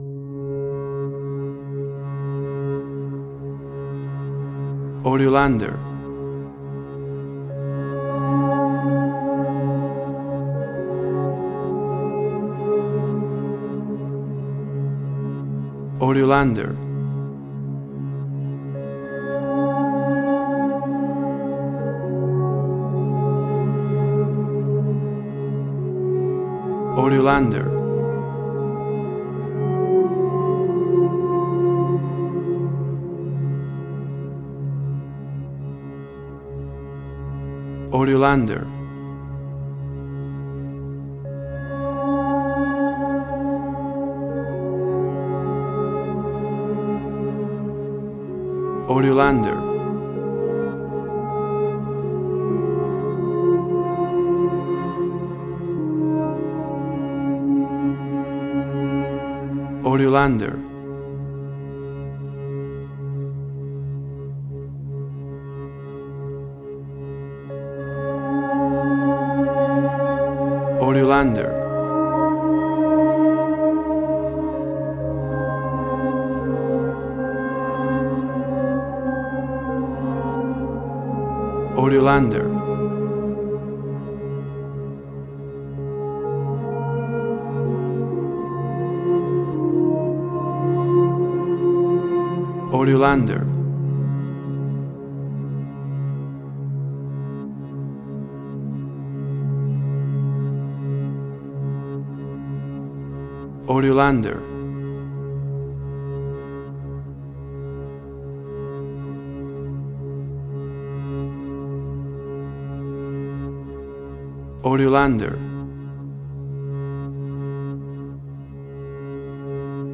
Suspense, Drama, Quirky, Emotional.
WAV Sample Rate: 16-Bit stereo, 44.1 kHz